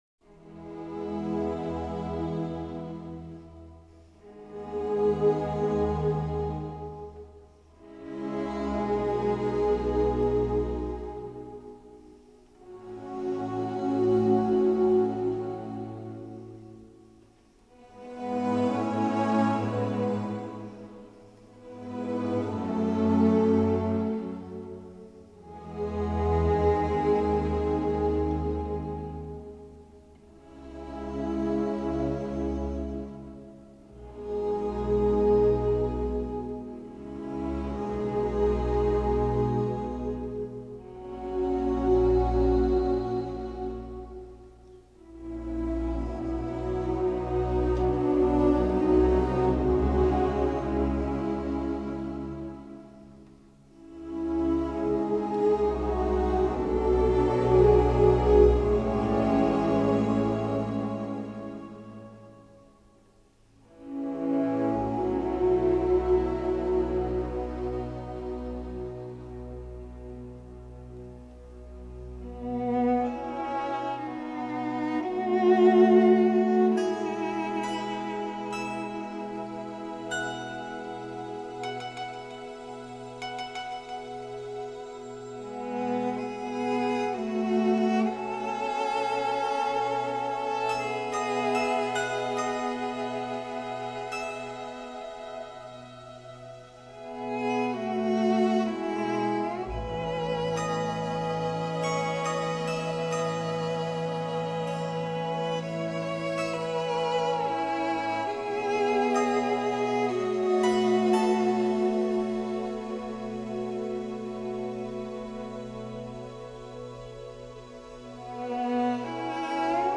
Genre: Soundtrack/Pop